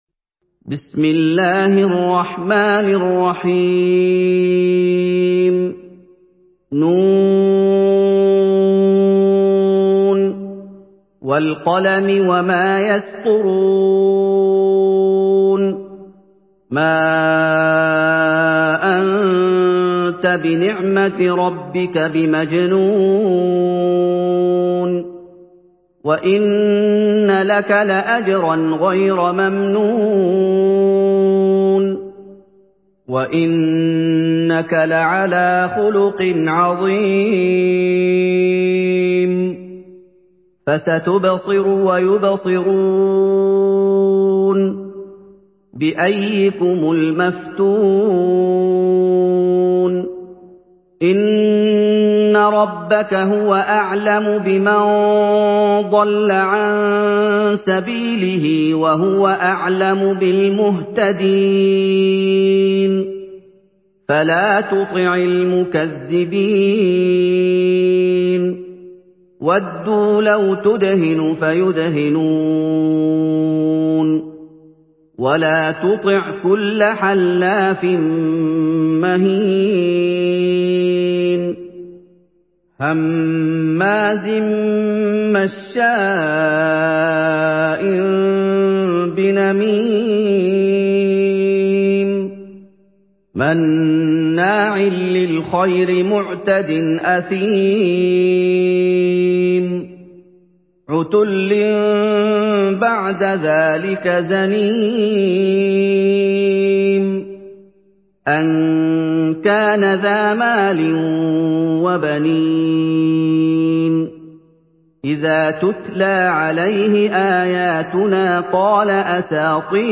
Surah আল-ক্বালাম MP3 by Muhammad Ayoub in Hafs An Asim narration.
Murattal